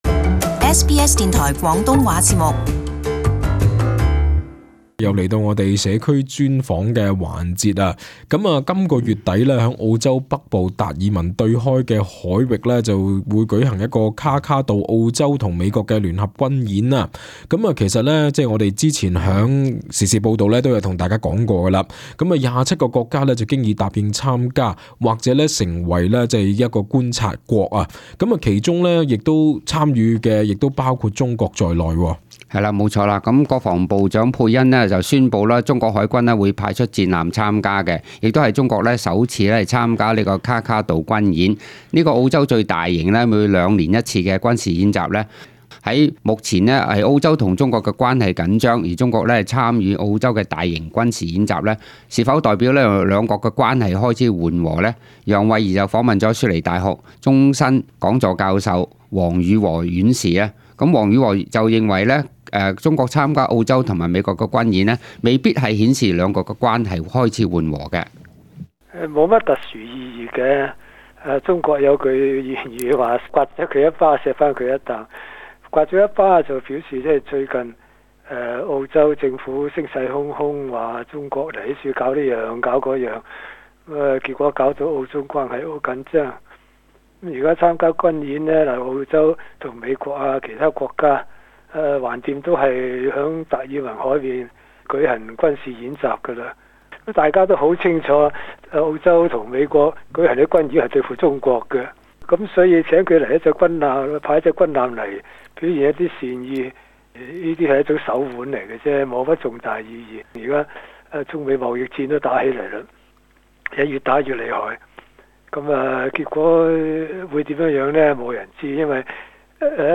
【社區專訪】澳中關係緩和？